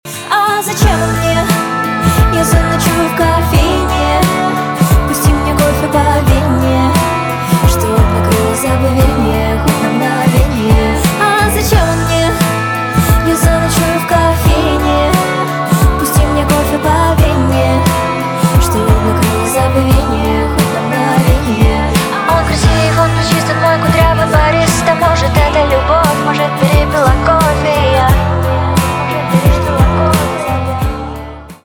инди
гитара , барабаны